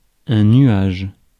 Ääntäminen
Ääntäminen France (Paris): IPA: [œ̃ ny.aʒ] Tuntematon aksentti: IPA: /nɥaʒ/ Haettu sana löytyi näillä lähdekielillä: ranska Käännös Substantiivit 1. pilv 2. vari Muut/tuntemattomat 3. parv Suku: m .